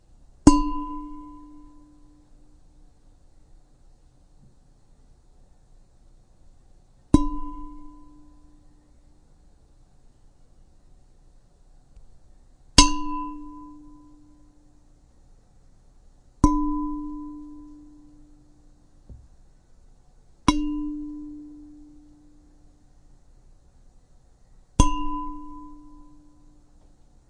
金属钟 " 单钟1
描述：打一单钟1
标签： 叮当
声道立体声